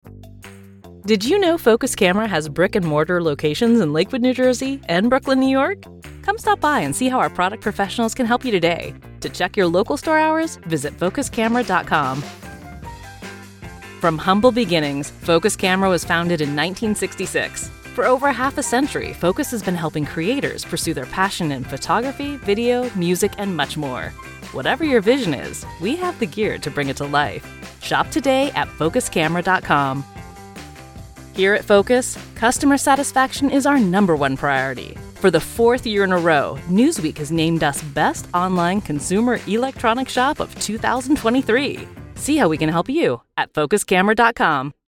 Hold Message Demo
Young Adult
Middle Aged
I specialize in commercial reads, corporate narration, and IVR, delivering a friendly, conversational tone like your best friend or trusted advisor.